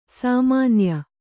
♪ sāmānya